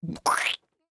add SFX
splat-v3.ogg